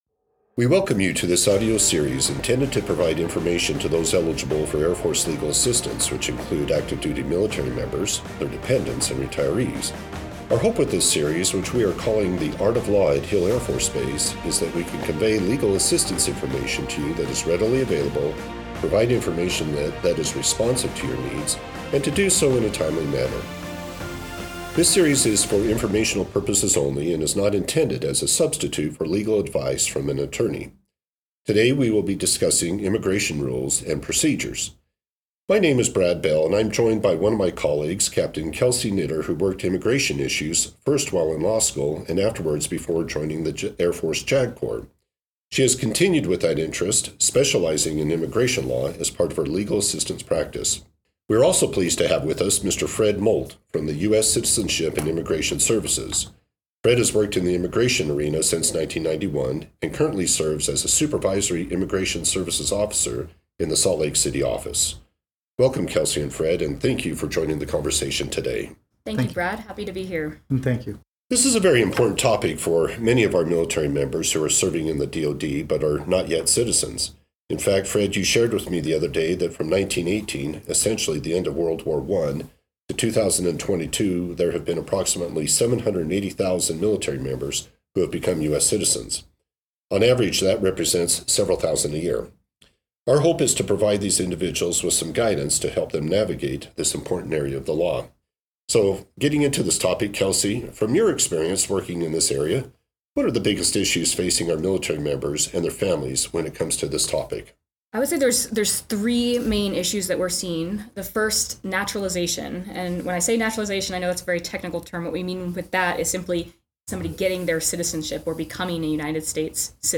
In this episode, we take a look at the immigration rule and procedures. We are joined by a JAG attorney and a member from USCIS to help answer common questions concerns.